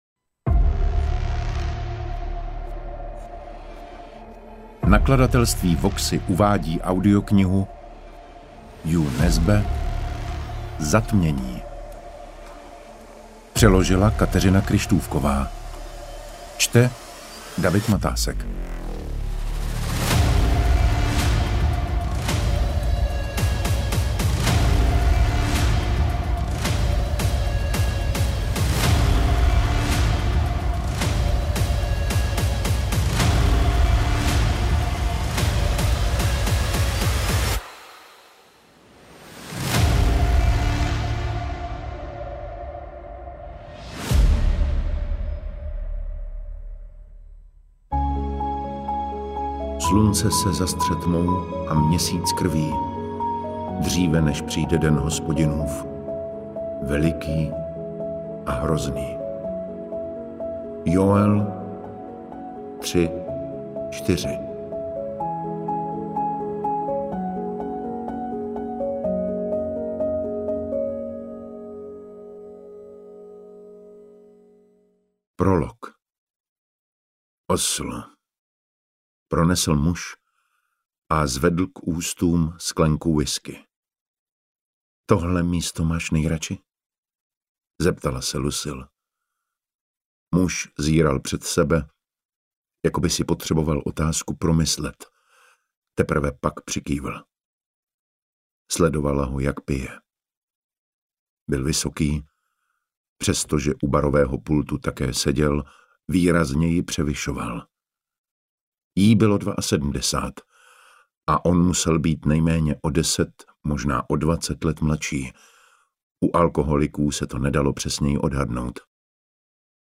Interpret:  David Matásek
AudioKniha ke stažení, 57 x mp3, délka 18 hod. 42 min., velikost 1021,9 MB, česky